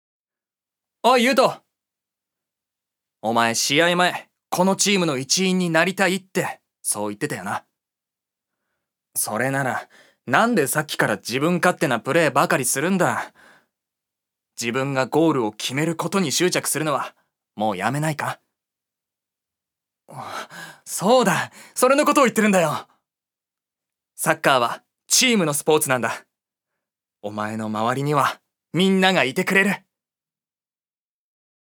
所属：男性タレント
セリフ１